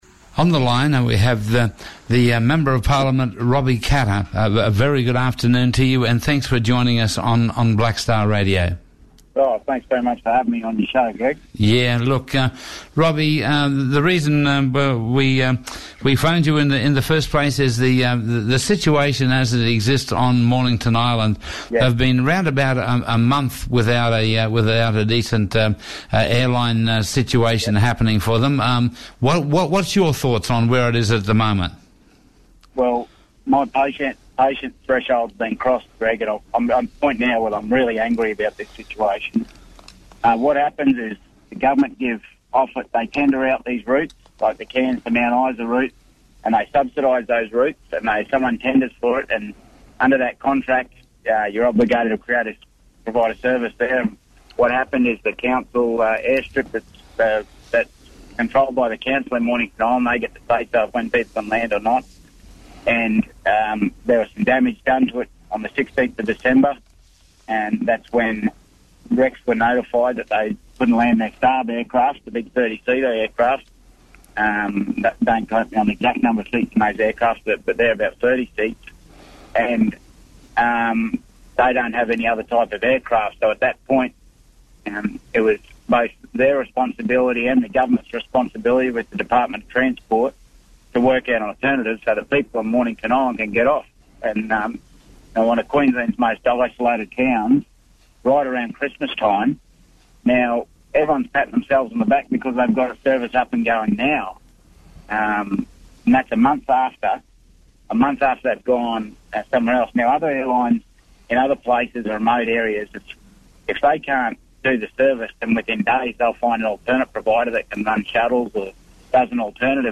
Gulf & Cape Leaders spoke with Black Star Radio on the re-opening of the Islands Airport.
State Member for Mt Isa MP Rob Katter on Black Star Breakfast 18 January 2016